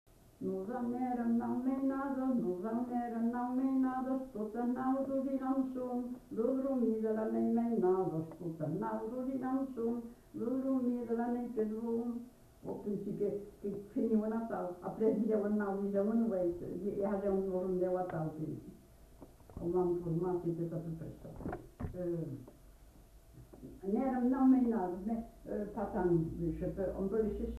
Lieu : Houeillès
Genre : chant
Effectif : 1
Type de voix : voix de femme
Production du son : chanté
Danse : rondeau